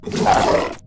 HighGrunt.mp3